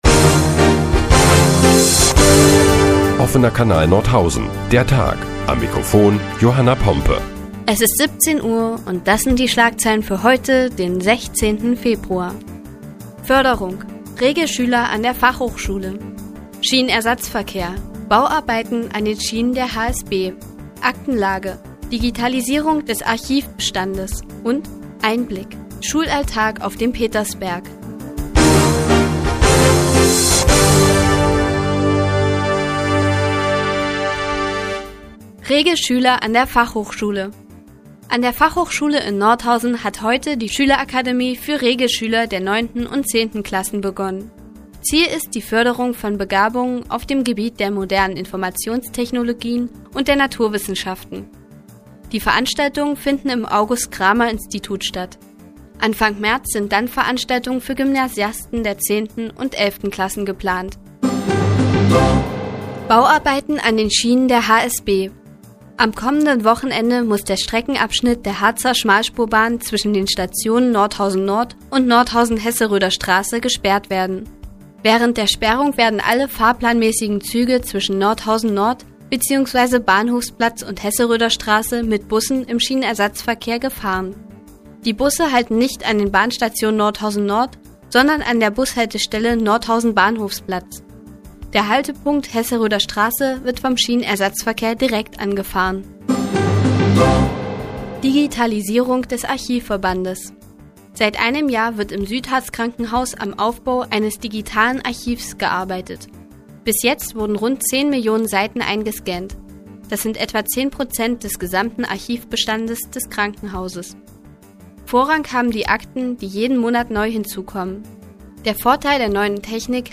Die tägliche Nachrichtensendung des OKN ist nun auch in der nnz zu hören. Heute geht es unter anderem um die Förderung von Regelschülern und Bauarbeiten an den Schienen der HSB.